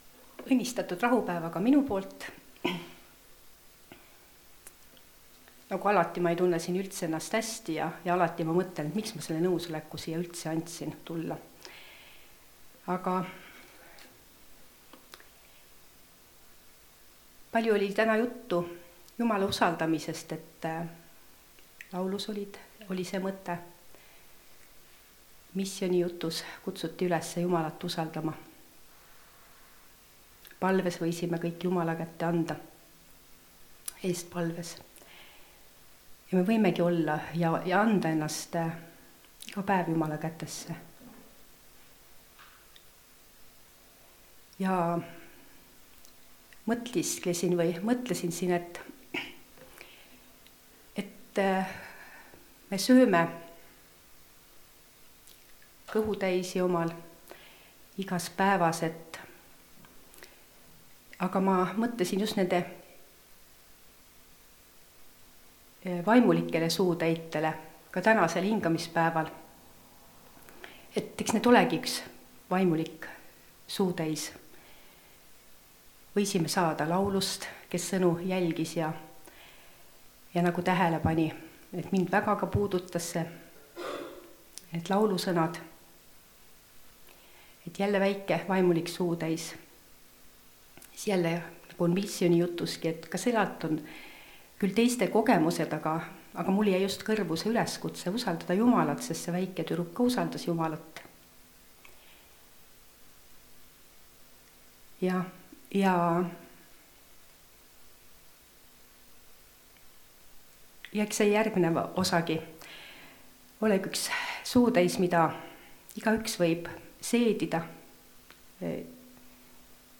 Teadaoleva patu võitmine (Rakveres)
Jutlused